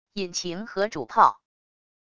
引擎和主炮wav音频